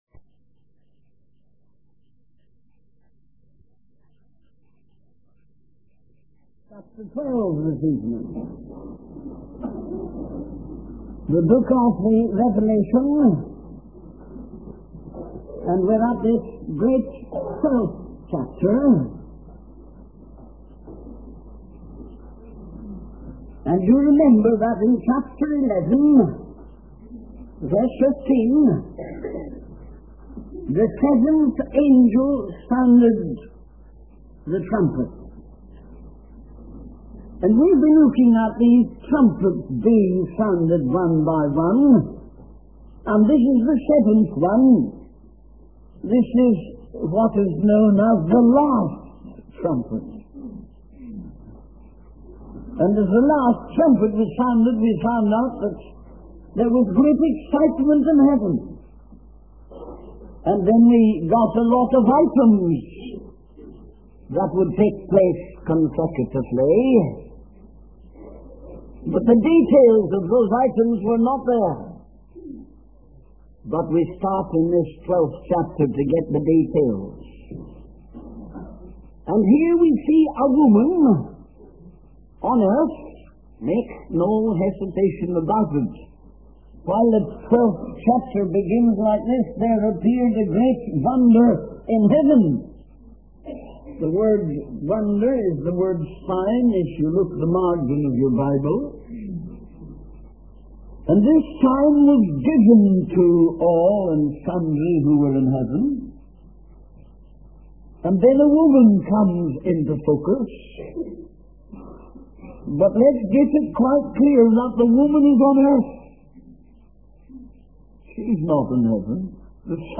In this sermon, the speaker discusses the battle between good and evil as described in the book of Revelation. He focuses on the imagery of a woman clothed with the sun and a great red dragon with seven heads and ten horns. The speaker emphasizes the importance of fellowship and agreement with God, as symbolized by the number three representing the Trinity.